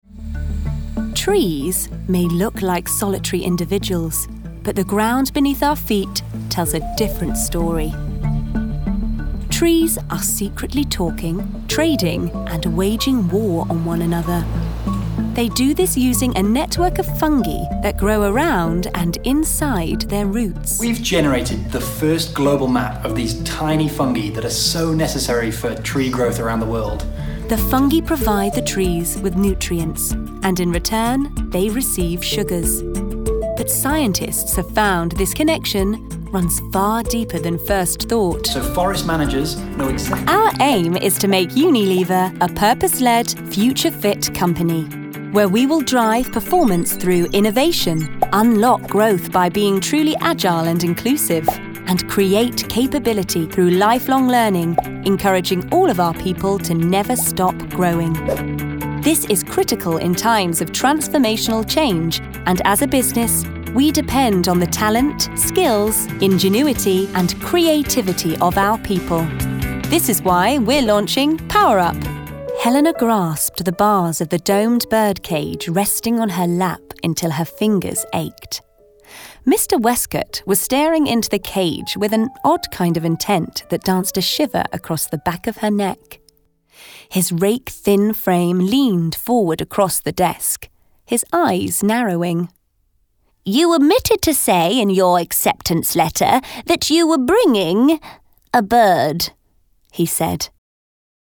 Narration Showreel
Female
British RP
Neutral British
Bright
Friendly
Confident
Warm